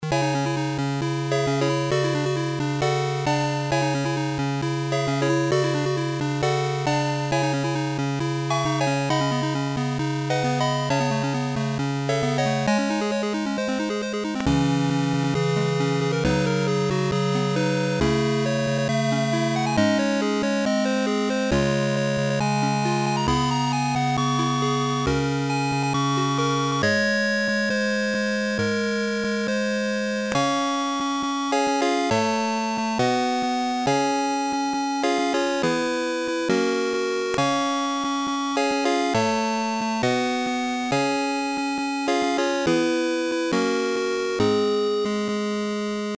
i added attack and decay to my adlib code
thanks. it's generating square waves though, and i think i should change it to sine.
fake86-adlib(attack-decay).mp3